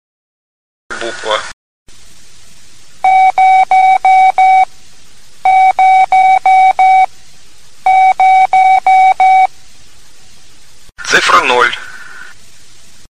Предлагаю для обучения приема использовать еще большее приближение к нашим занятиям - сперва звучит слово "Буква", затем три раза повторяется морзе и в это время называем букву (или цифру), затем слушаем правильный ответ.